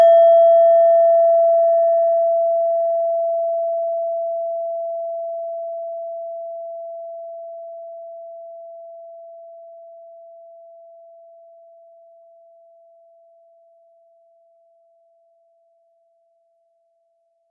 Klangschale Nepal Nr.6
Klangschale-Gewicht: 470g
Klangschale-Durchmesser: 11,2cm
(Ermittelt mit dem Filzklöppel)
klangschale-nepal-6.wav